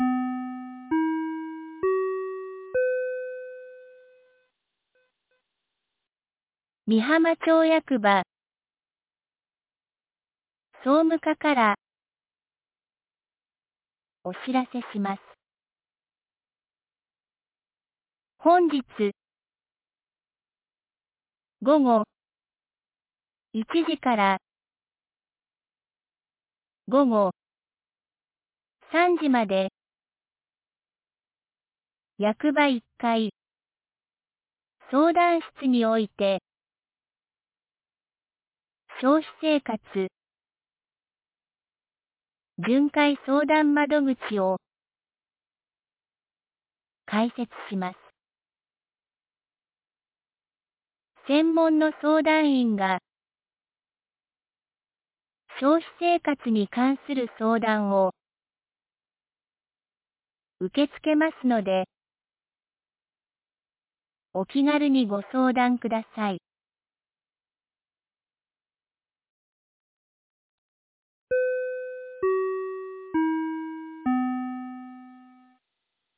美浜町放送内容 2026年03月04日11時46分 （町内放送）消費生活相談 | 和歌山県美浜町メール配信サービス
2026年03月04日 11時46分に、美浜町より全地区へ放送がありました。